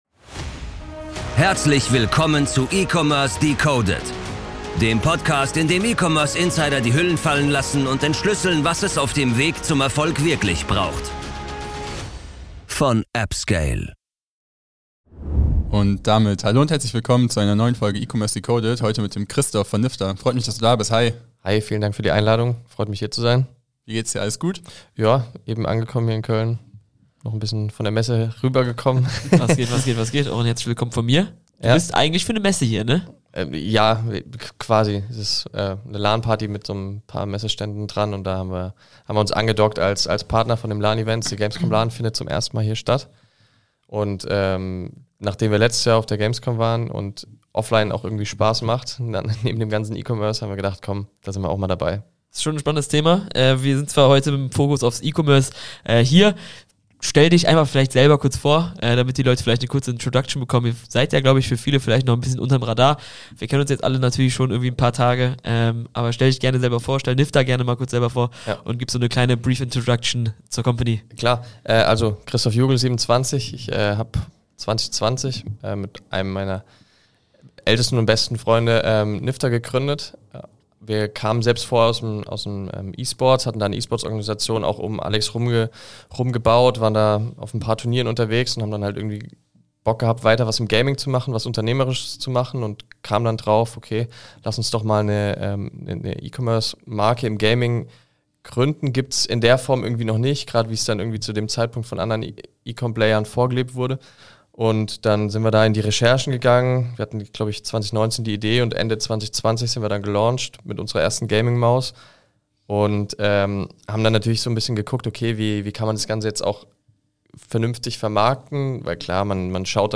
Ein tolles Gespräch über Markenaufbau in schwierigem Marktumfeld sowie ein Update zu TikTok.